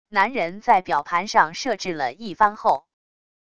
男人在表盘上设置了一番后wav音频